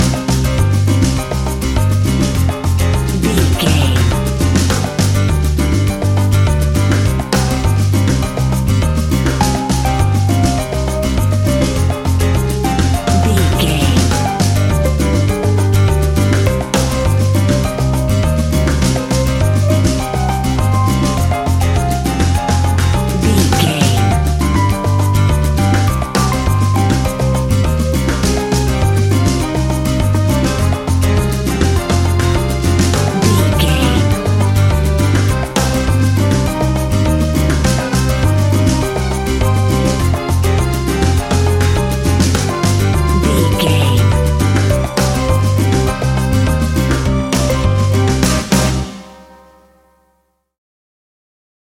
An exotic and colorful piece of Espanic and Latin music.
Ionian/Major
D
flamenco
salsa
maracas
percussion spanish guitar